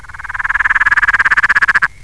Identify the following calls of frogs.
Frog 2;